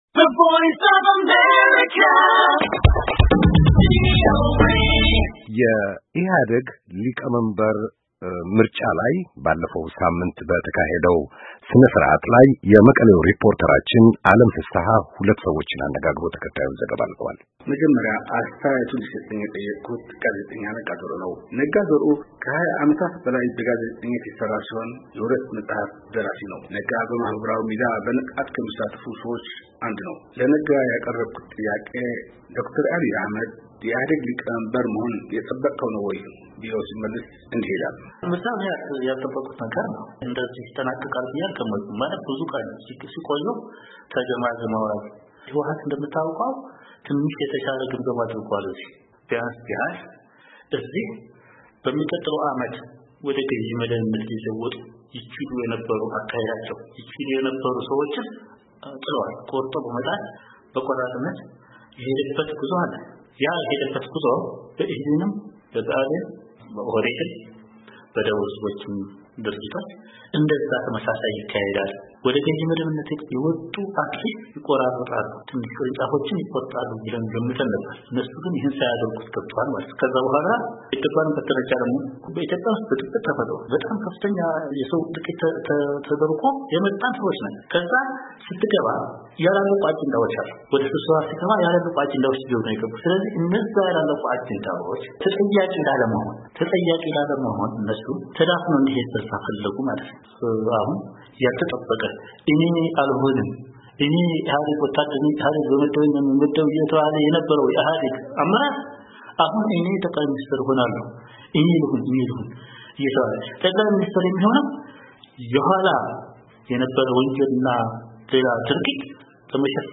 ሁለት የመቀሌ ነዋሪዎች በዶ/ር አብይ የኢህአዴግ ሊቀመንበርነት ምርጫ ላይ የሰጡት አስተያየት